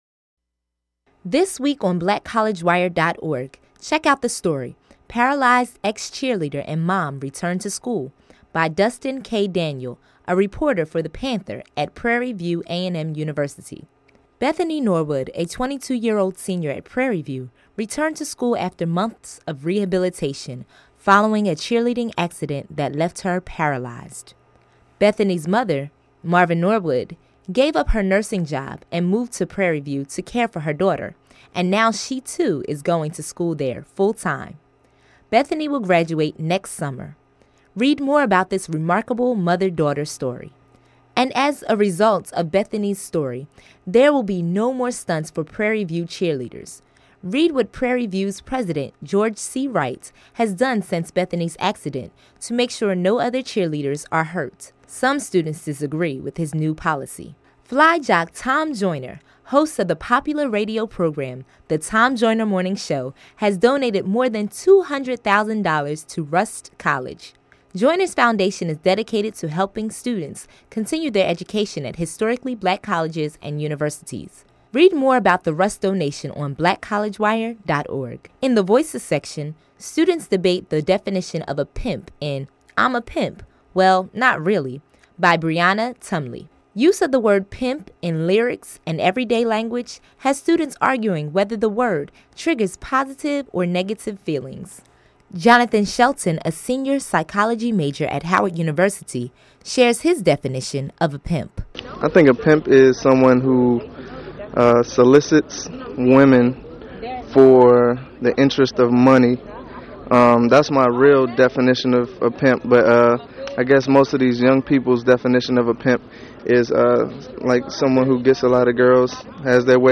News Summary of the Week